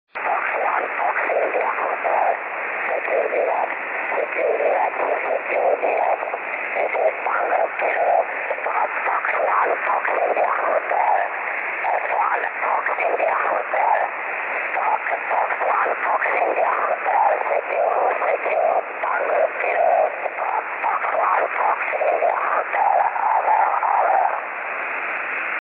REGISTRAZIONI DEGLI ECHI LUNARI DEI BEACONS E DEI QSO